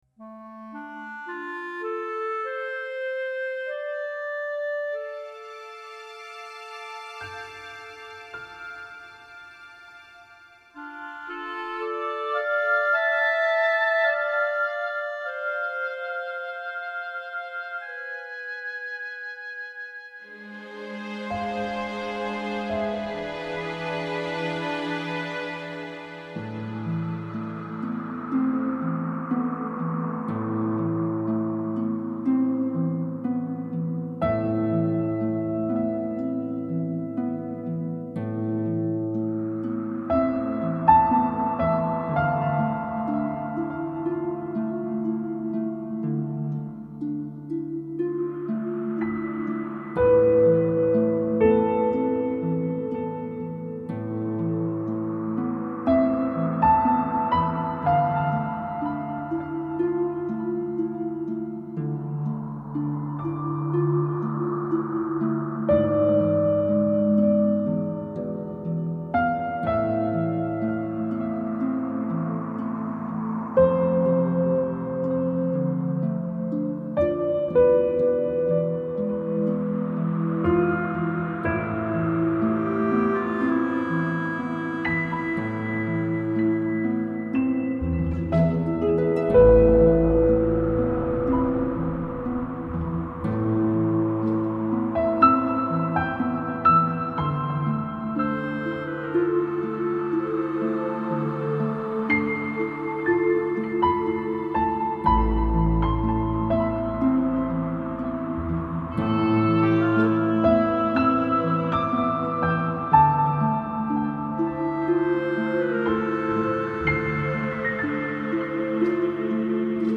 Catégorie : musique